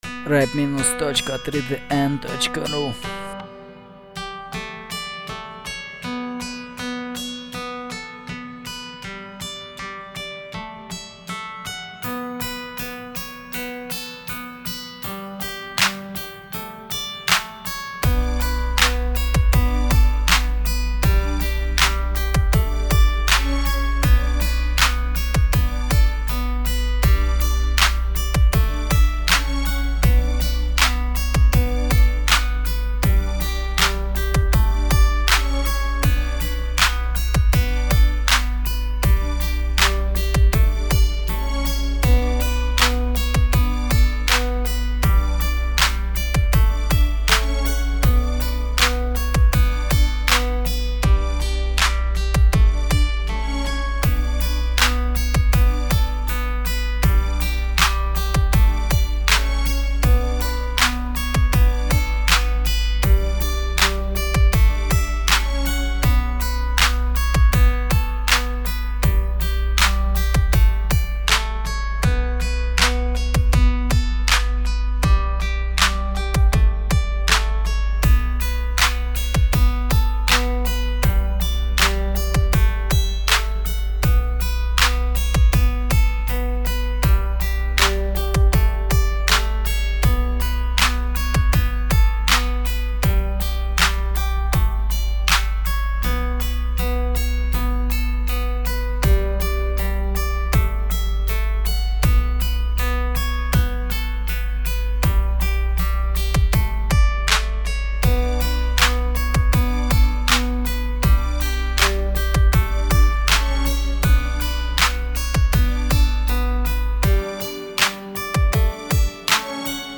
лирический рэп минус. гитара, скрипка
Каналы : 2 (стерео)
голос только в начале, легко вырезать